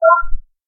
minertools_scan.ogg